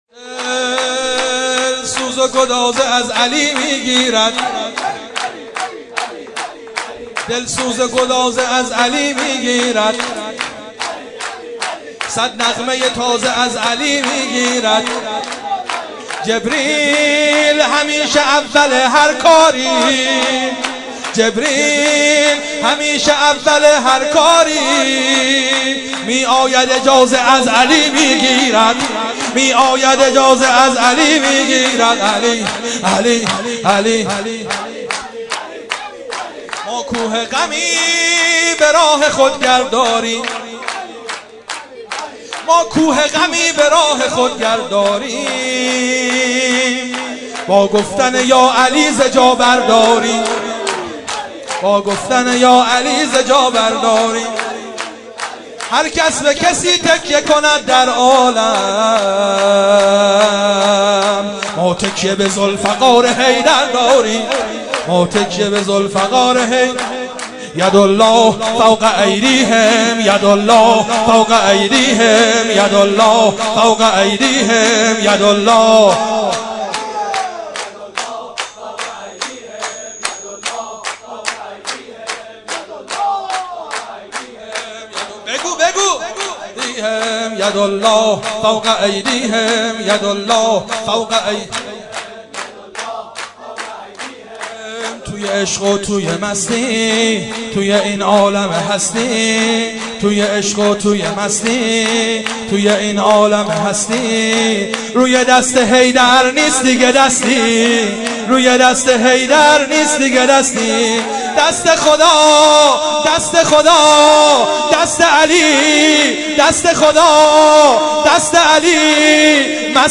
سرود - دل سوز و گداز از علی می گیرد